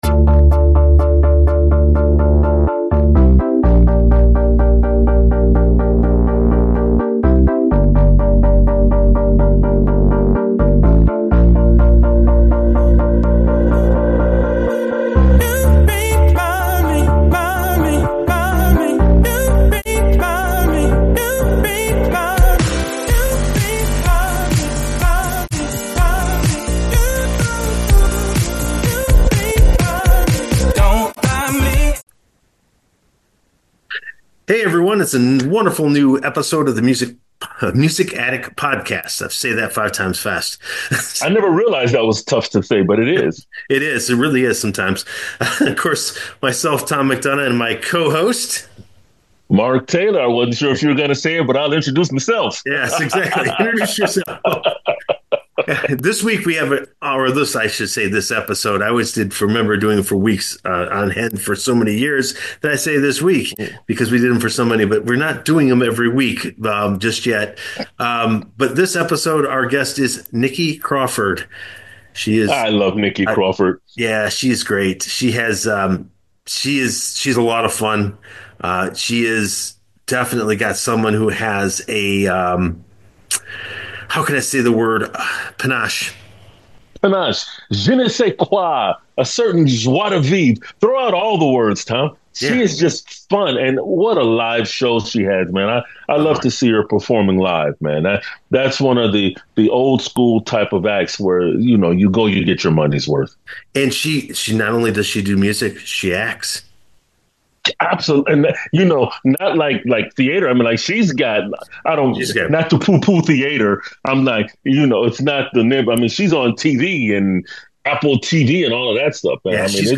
Join us as we explore her journey, her mission to create feel-good music, and the passion that drives her artistry. Don’t miss this engaging conversation filled with insights and inspiration!